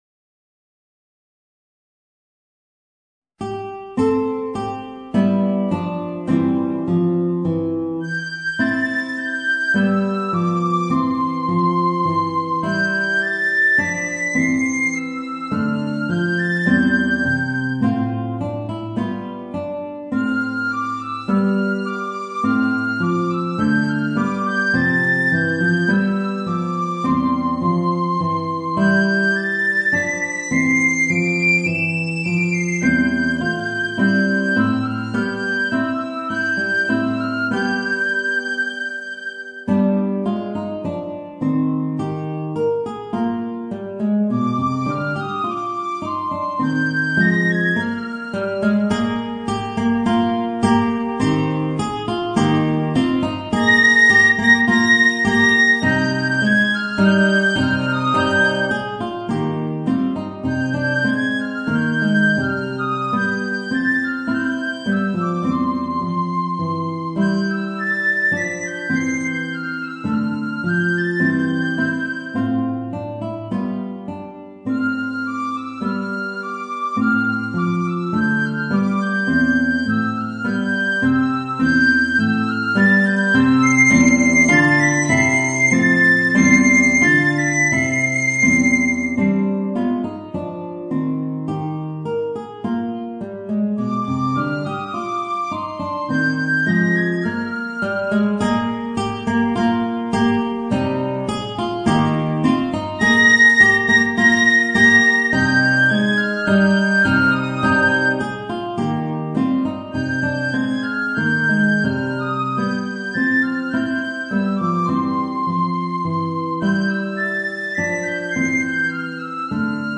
Voicing: Guitar and Piccolo